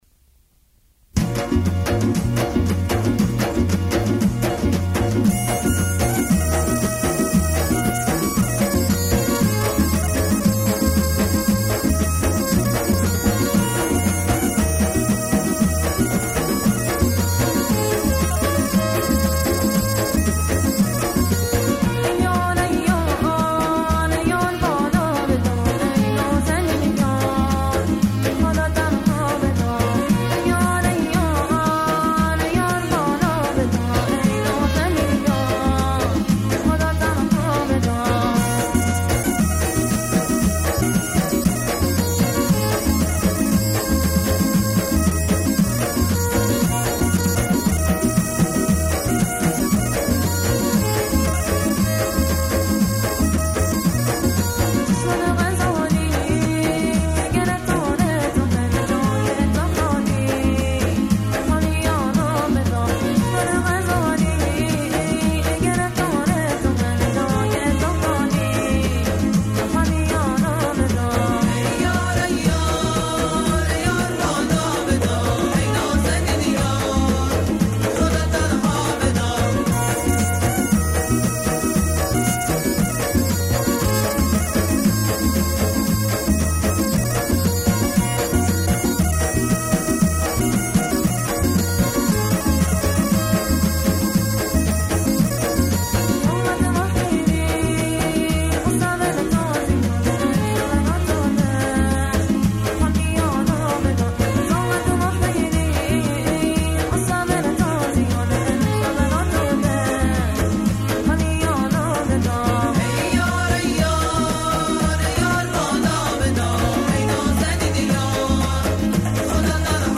اهنگ بستکی ای یار ای یار